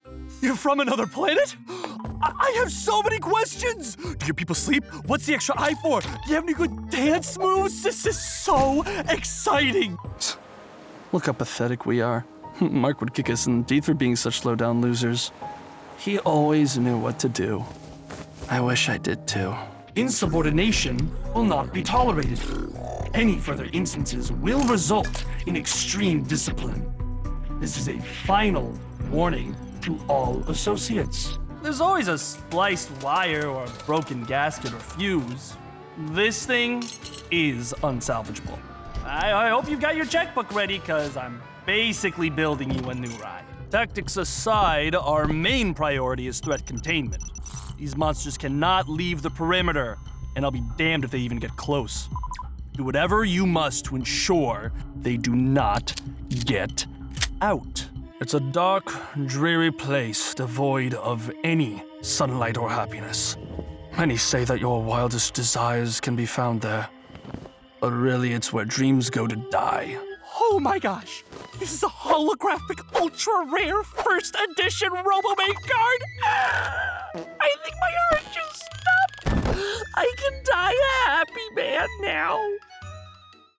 Character Reel
Rode NT1
Hardwood booth treated with:
4 96x80 acoustic blankets